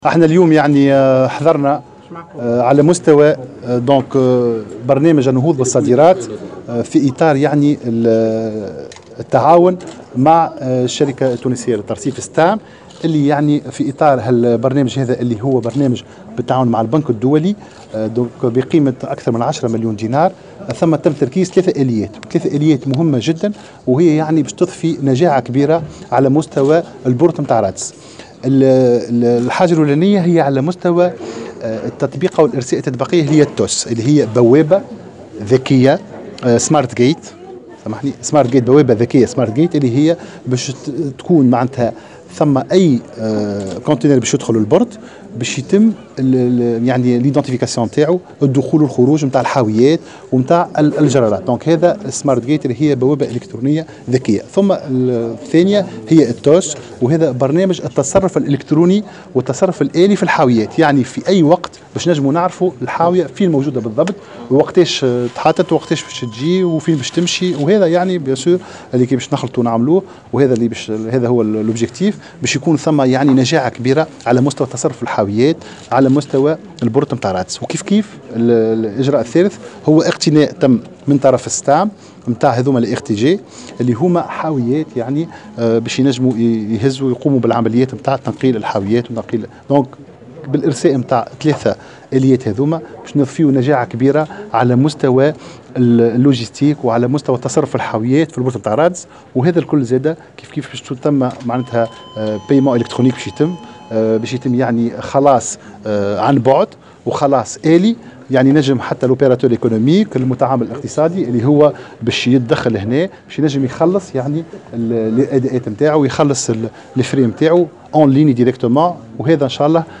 و أكد وزير التجارة عمر الباهي في تصريح لمراسلة الجوهرة "اف ام" أن تركيز هذه الاليات يأتي في اطار برنامج للنهوض بالصادرات بالتعاون مع البنك الدولي بقيمة أكثر من 10 مليون دينار ومن المنتظر أن تضفي نجاعة كبيرة على ميناء رادس.